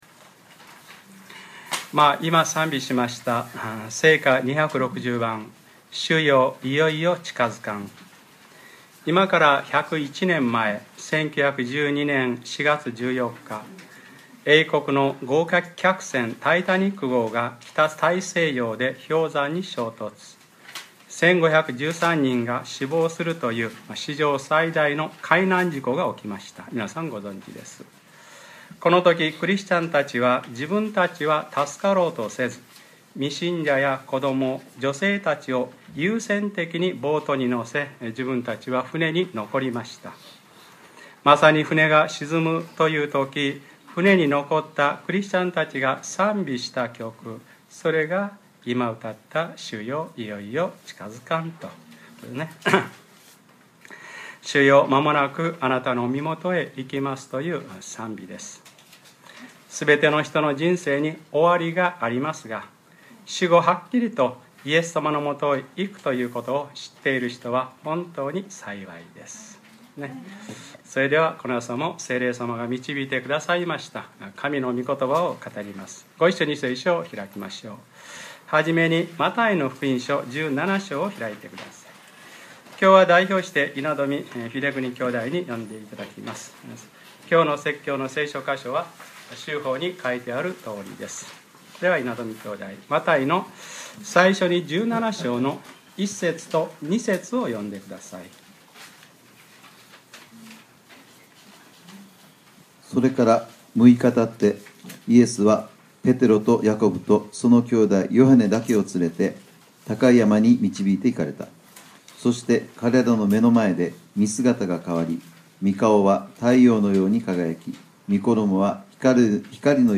2013年7月21日(日）礼拝説教 『神がご自身の血をもって買い取られた』 | クライストチャーチ久留米教会